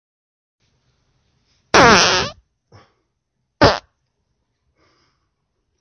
真实的屁 " 屁14
描述：真屁
Tag: 现实 放屁 真正